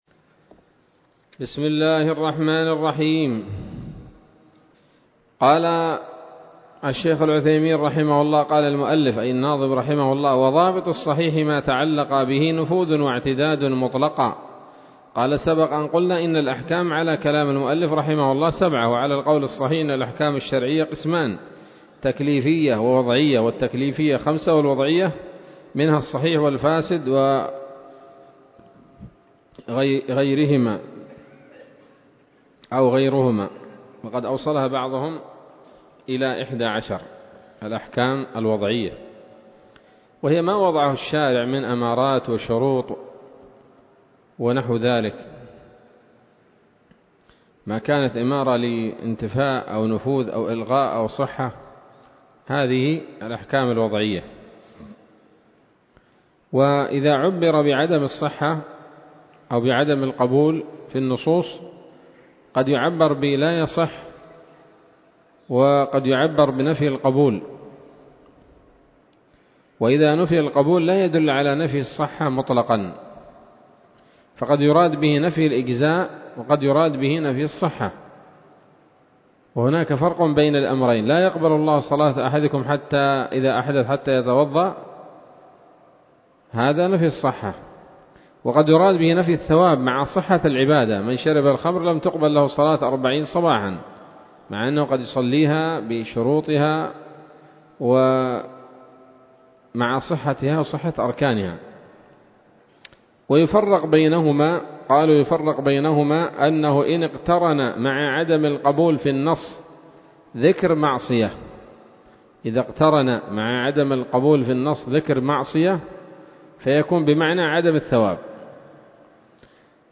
الدرس السادس عشر من شرح نظم الورقات للعلامة العثيمين رحمه الله تعالى